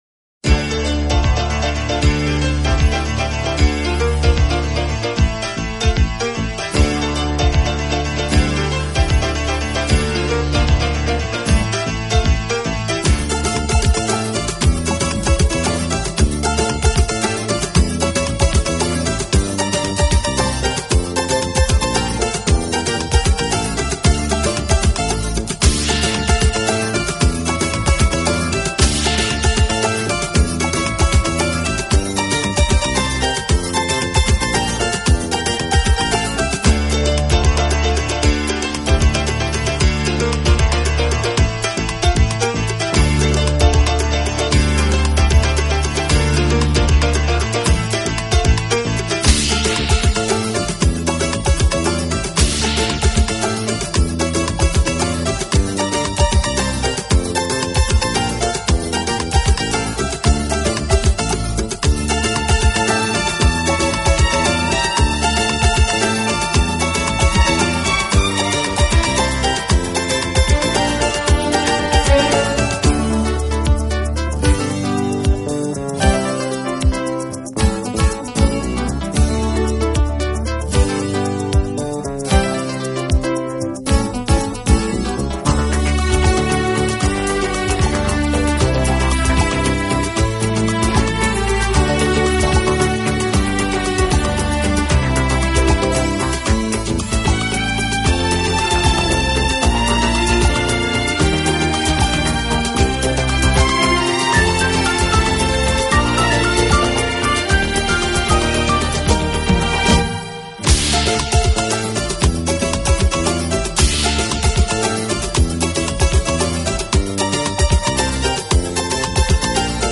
【顶级轻音乐】